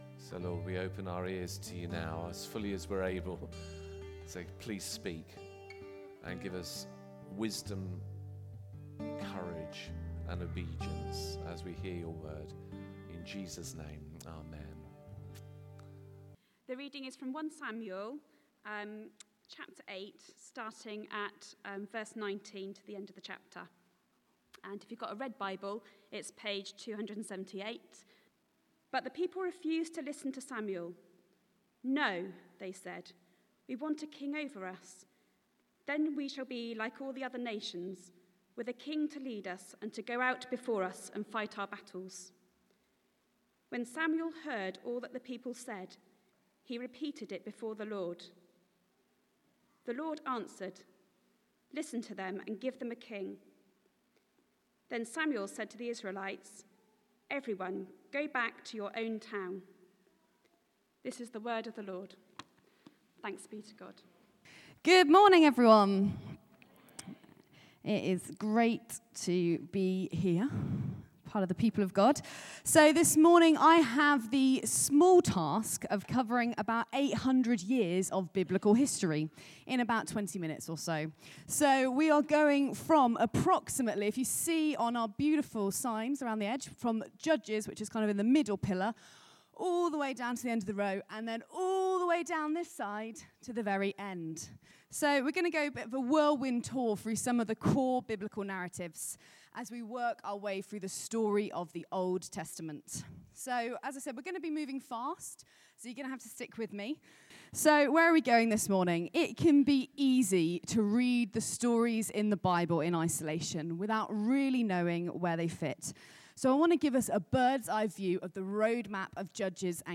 1 Samuel 8:19-22 Service Type: Sunday 11:00am « Story of the Old Testament 2